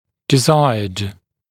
[dɪ’zaɪəd][ди’зайэд]желанный, желательный, искомый